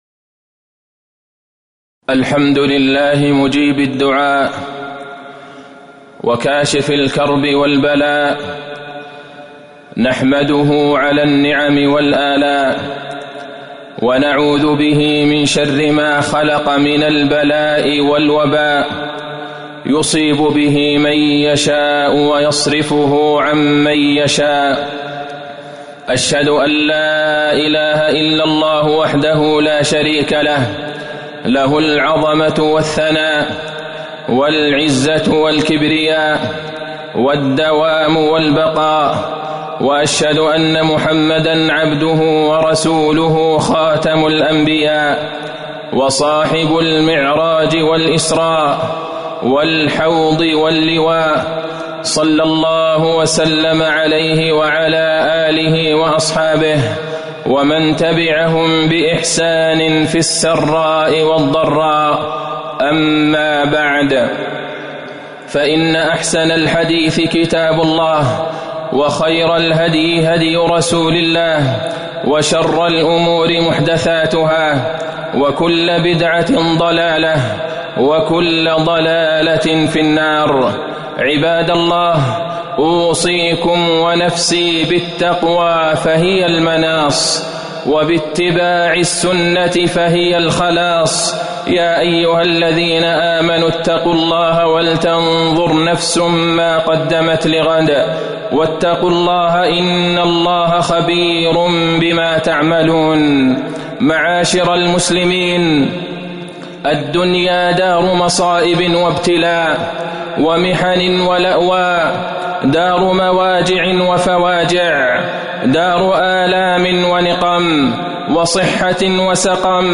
تاريخ النشر ١٨ رجب ١٤٤١ هـ المكان: المسجد النبوي الشيخ: فضيلة الشيخ د. عبدالله بن عبدالرحمن البعيجان فضيلة الشيخ د. عبدالله بن عبدالرحمن البعيجان الصبر على البلاء